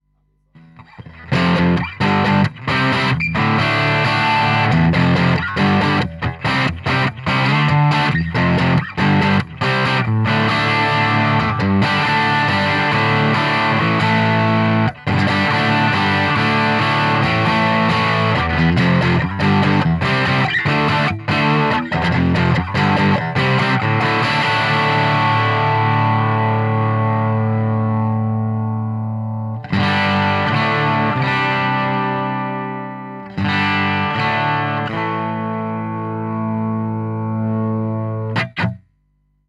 Wer die Originalverstärker kennt, hört, dass die Klänge sehr ordentlich gemodelt werden und die typische Marshall-Charakteristik eindeutig erkennbar ist.
• Gibson Les Paul
• Shure SM57